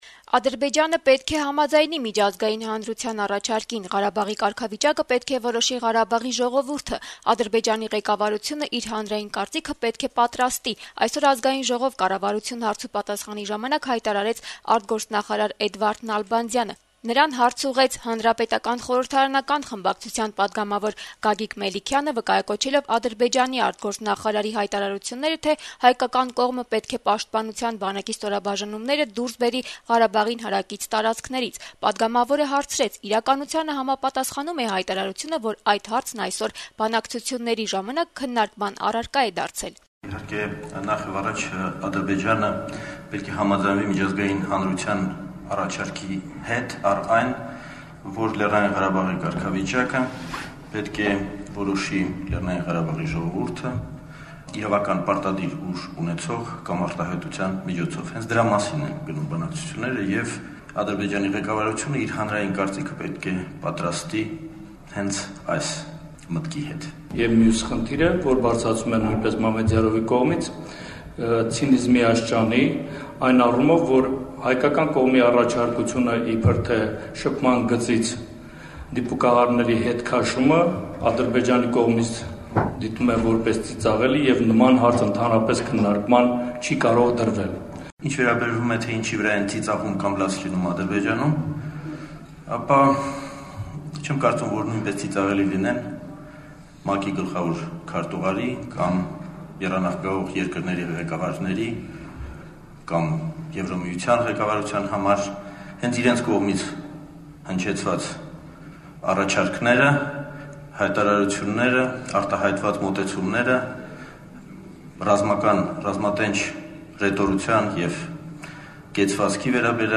Ազգային ժողով-կառավարություն հարցուպատասխանի ընթացքում Հայաստանի արտգործնախարարը անդրադարձավ Ադրբեջանի արտգործնախարարի հայտարարություններին: